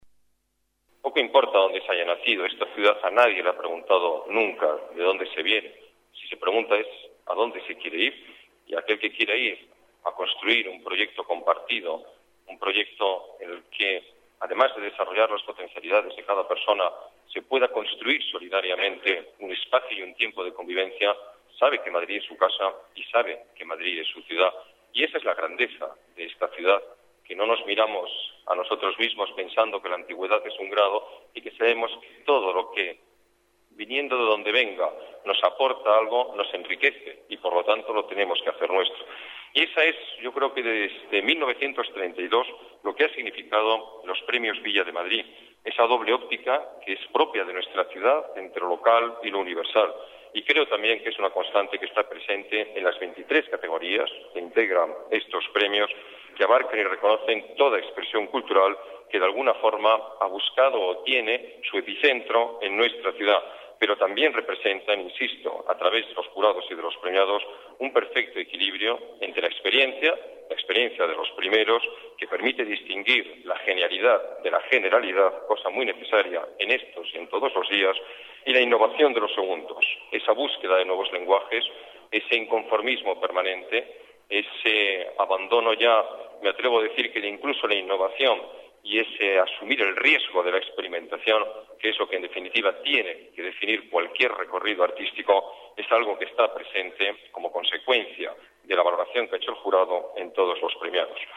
Nueva ventana:Declaraciones del alcalde de la Ciudad de Madrid, Alberto Ruiz-Gallardón: Premios Villa de Madrid